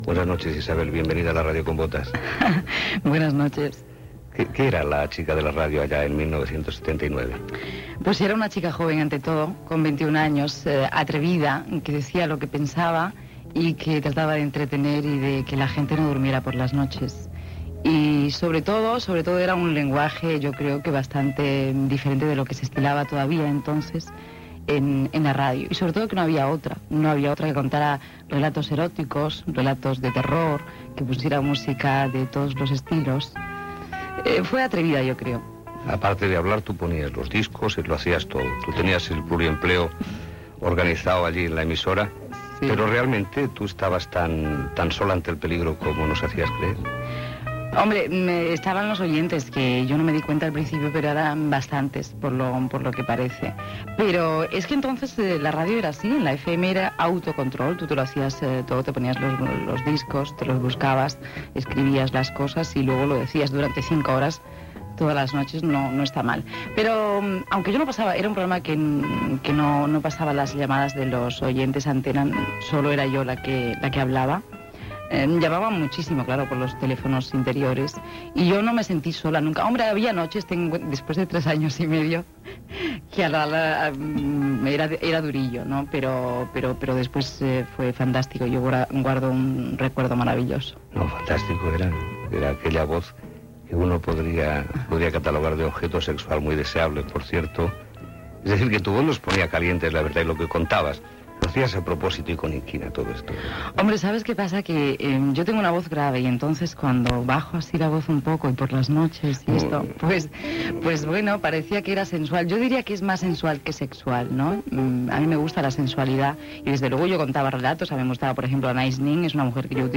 Entrevista a Isabel Gemio, qui recorda els seus inicis i el programa "La chica de la radio", que presentava amb el nom d'Isabel Garbí